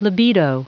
Prononciation du mot libido en anglais (fichier audio)
Prononciation du mot : libido